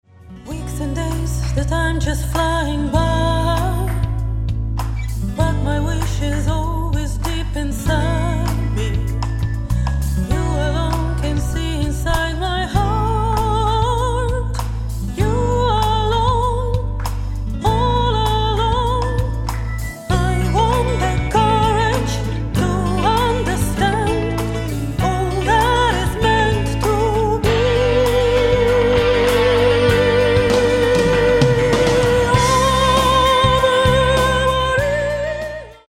Bass
Drums
Guitar
Keyboards
Backup vocals
Mastered at Abbey Road Studios, London